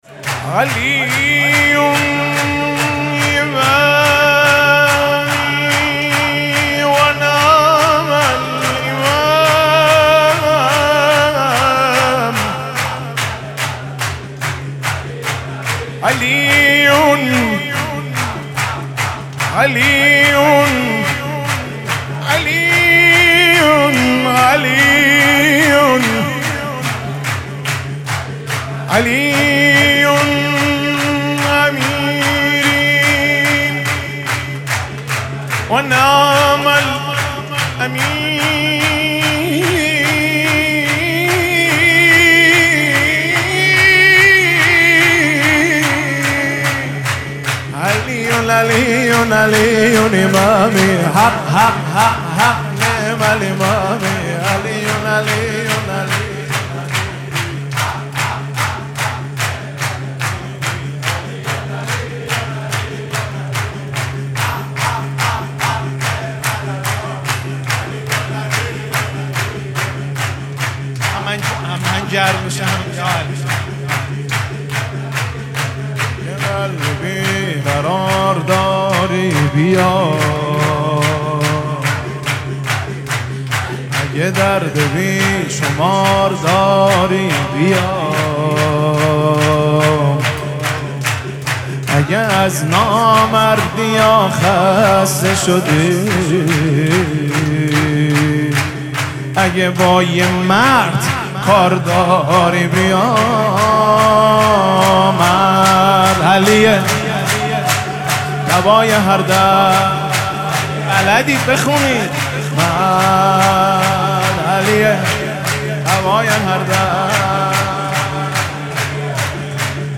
مراسم جشن شب ولادت حضرت امیرالمؤمنین علیه السلام
سرود
مداح